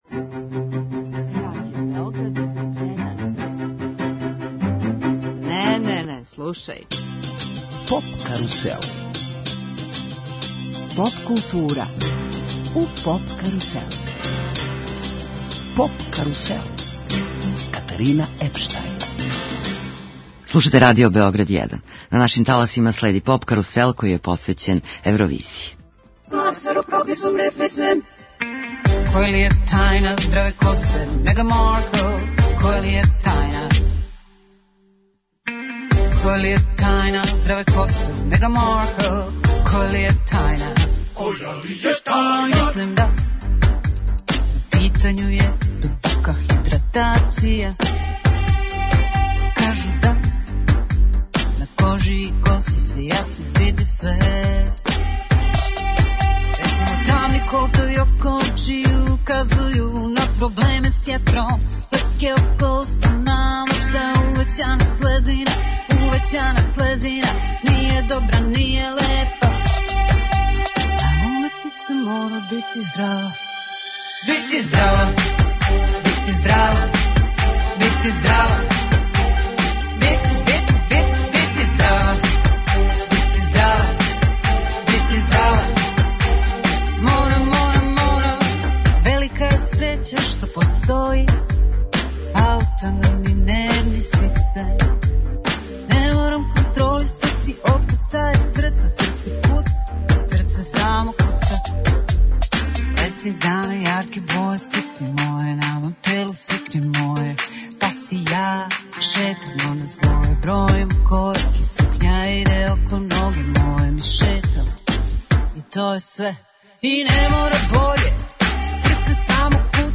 Уочи наступа на другој полуфиналној вечери, на Евросонгу, гошћа емисије је Ана Ђурић Констракта.